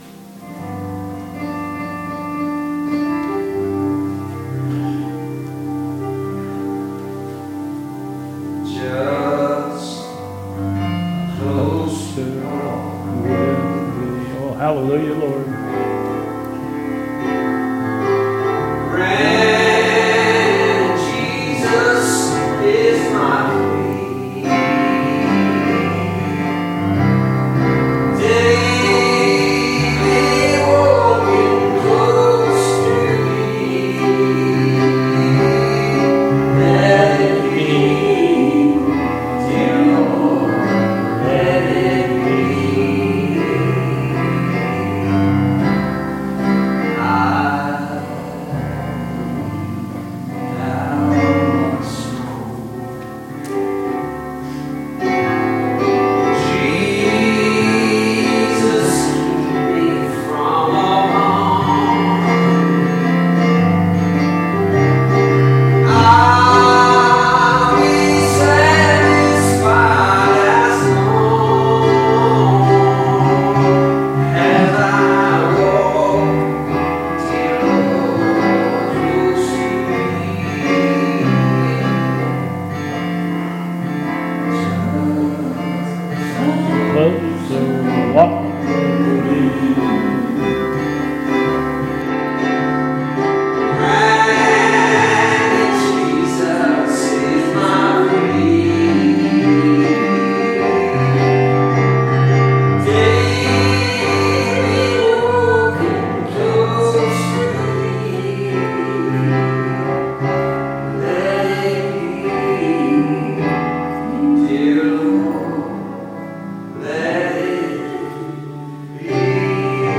Music Special